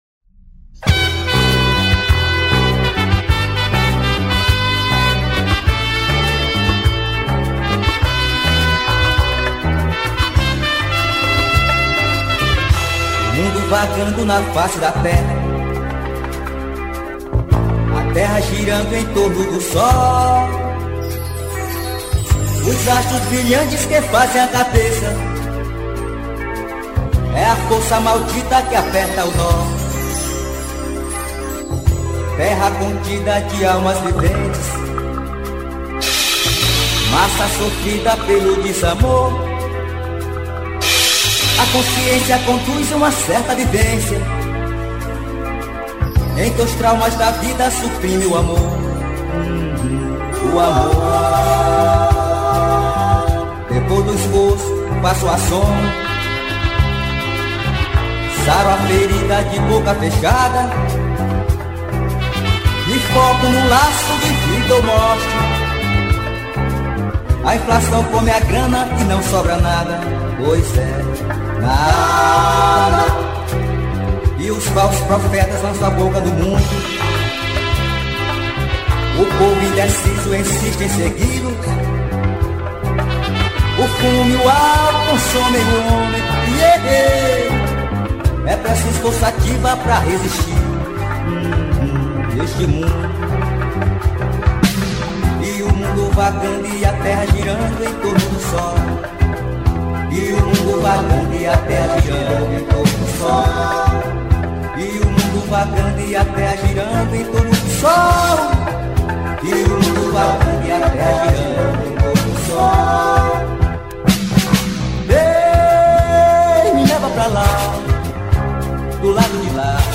2636   03:49:00   Faixa: 9    Rock Nacional